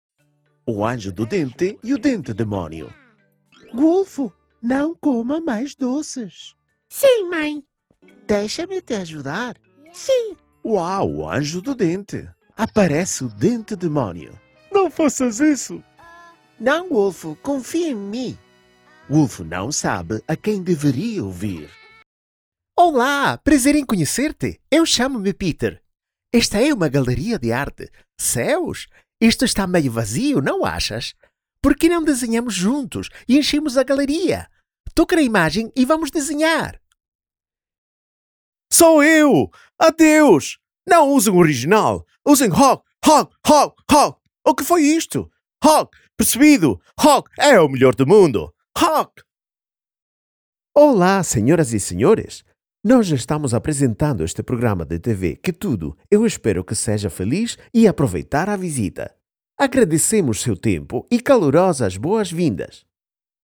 Stem
Commercieel, Opvallend, Toegankelijk, Veelzijdig, Vertrouwd